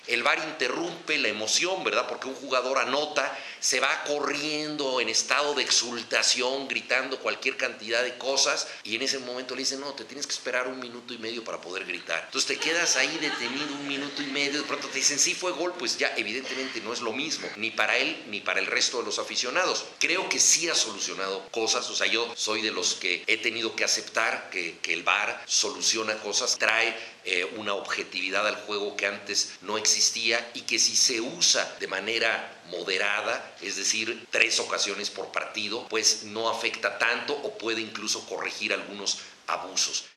Juan Villoro, en la Facultad de Ciencias Políticas y Sociales de la UNAM.